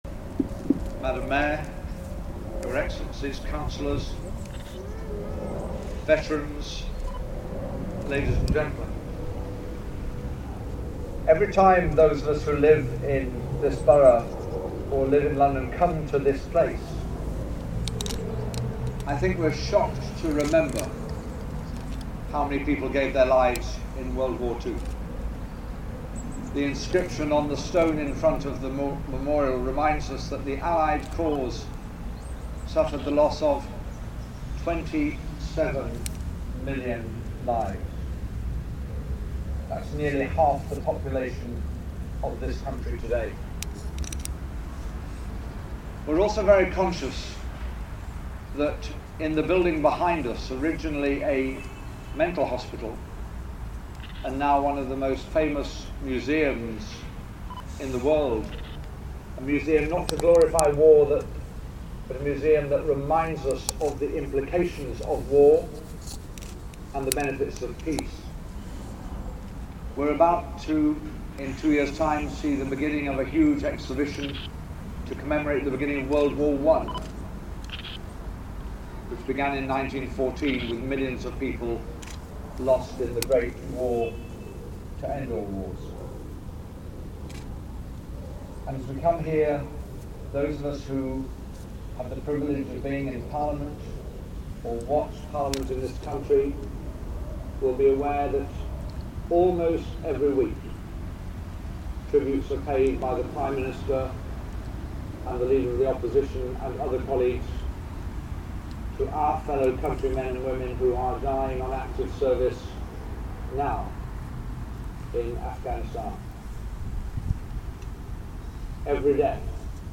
Simon Hughes MP speech at Soviet War Memorial